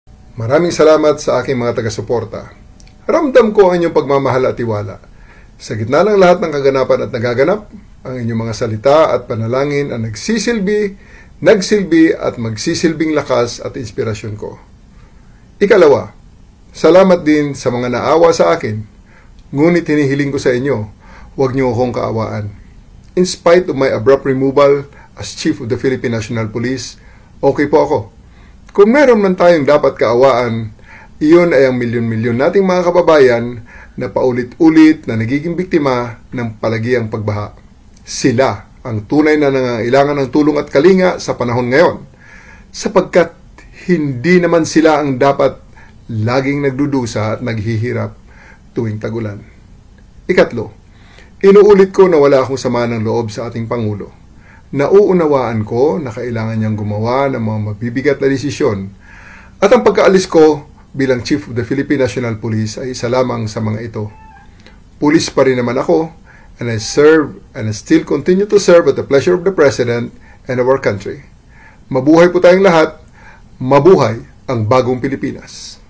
Former Philippine National Police chief Gen. Nicolas Torre III tells supporters “not to pity him” after being abruptly removed as the top cop earlier this week.